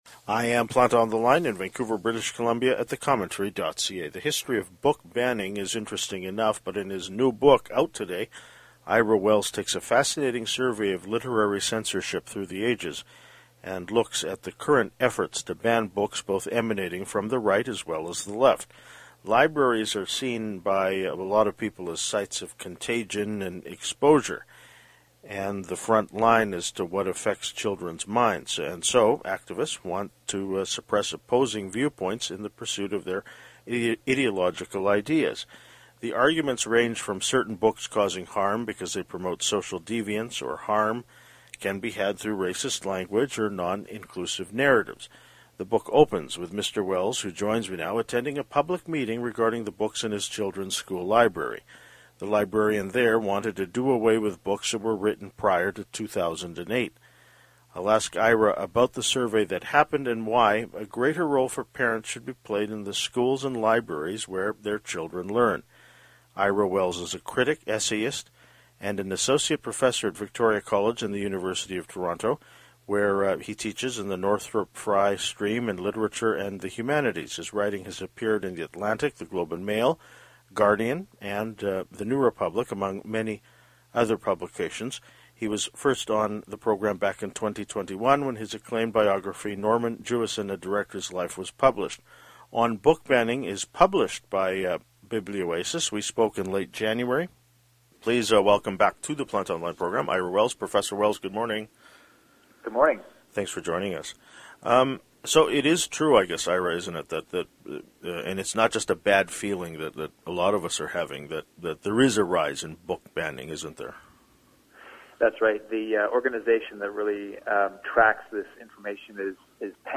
We spoke in late January.